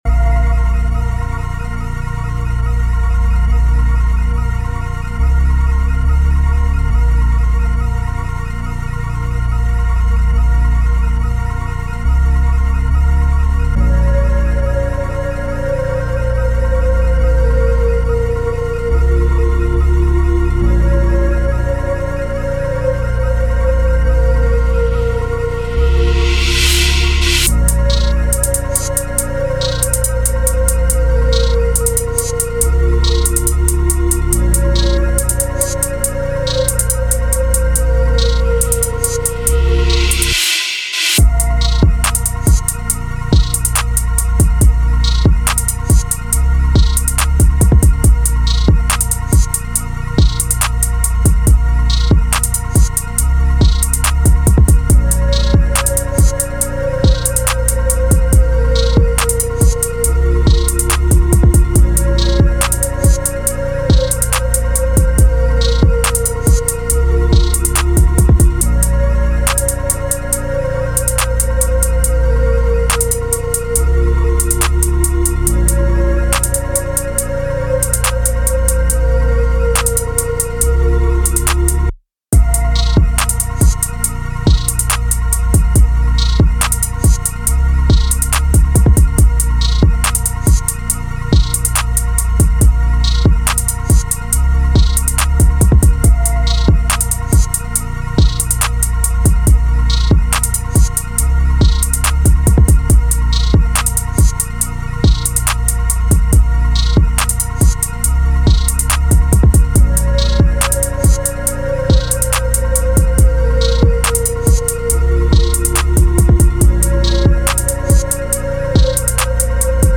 Trap Beats